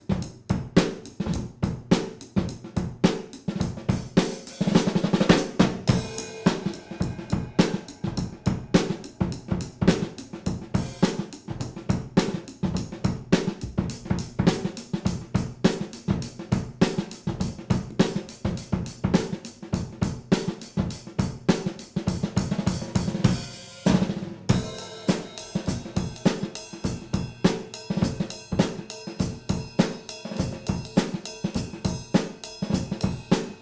First drum recording
2x overheads (SM81)
1x snare top (Shure 545D knockoff)
1x kick (Audix D6 knockoff)
No room treatment. Processed in Harrison Mixbus; tape and a bit of room emulation from IK Multimedia.
Pic attached just to get an idea of set-up... floor tom mic not used in mix, and obviously the snare and hats are not in playable positions lol.
Drums processing.mp3